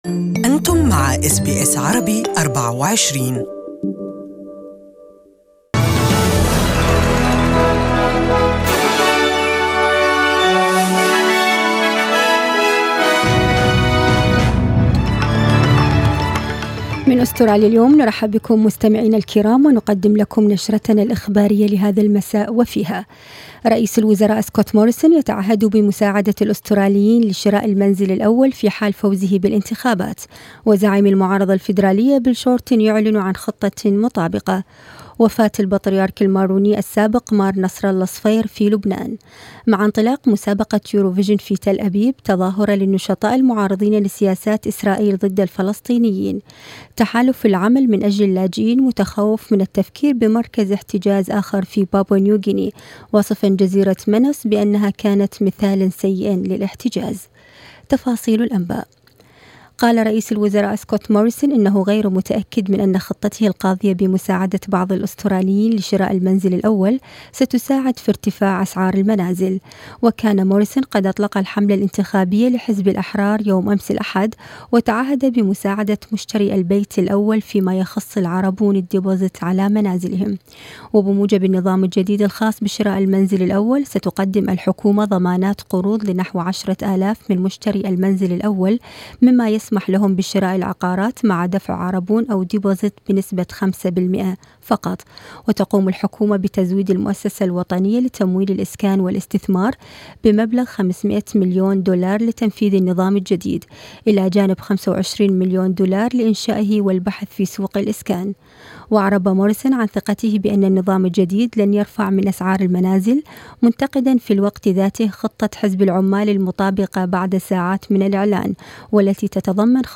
Evening News Bulletin: Leaders tussle over housing deposit scheme